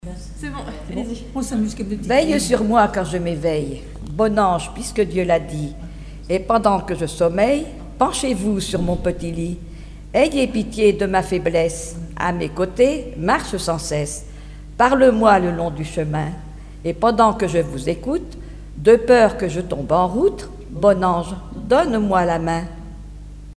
Mémoires et Patrimoines vivants - RaddO est une base de données d'archives iconographiques et sonores.
prière, cantique
Chansons et formulettes enfantines
Pièce musicale inédite